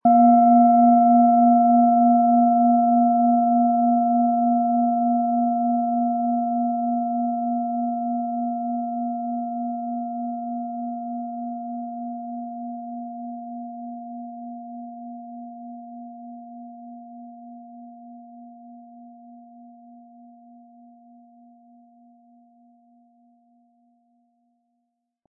Planetenschale® Sich selbst helfen können & Emotionen wahrnehmen mit Mond, Ø 14,9 cm, 320-400 Gramm inkl. Klöppel
Planetenton 1
Im Sound-Player - Jetzt reinhören können Sie den Original-Ton genau dieser Schale anhören.
Der kräftige Klang und die außergewöhnliche Klangschwingung der traditionellen Herstellung würden uns jedoch fehlen.
HerstellungIn Handarbeit getrieben
MaterialBronze